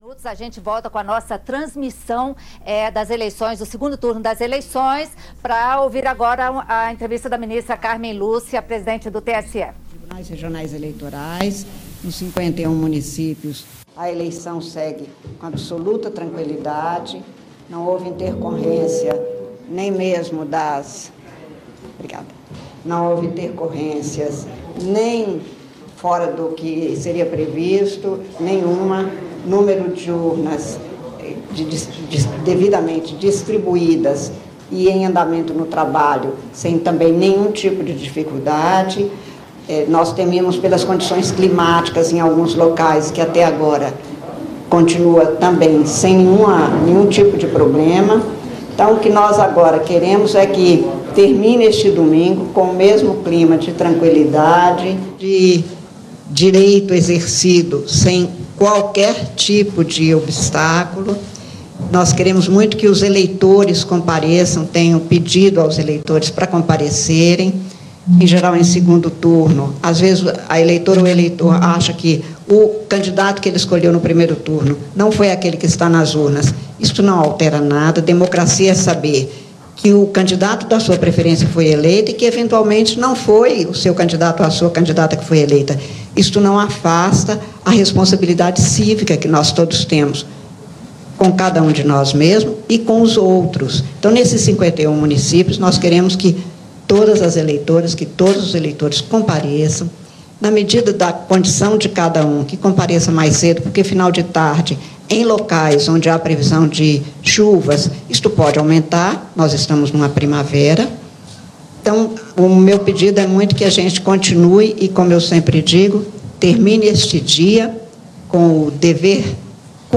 Em coletiva de imprensa, a presidente do TSE, ministra Cármen Lúcia, fez um balanço parcial do 2° turno até o momento. Ela destacou que não houve intercorrências fora do previsto. A ministra fez um chamado para que todos os eleitores exerçam sua atividade cívica e espera que as eleições se encerrem com tranquilidade, como está sendo até agora.
Pronunciamento